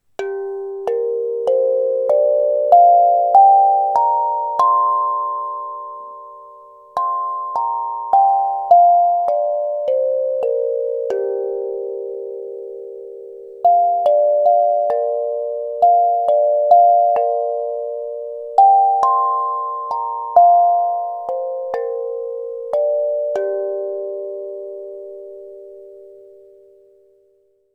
Its pre-tuned scale means there are no wrong notes, and the sound is mellow and soothing (like a soft steel pan drum). Includes a transportation bag and two soft rubber mallets for a full and tranquil tone.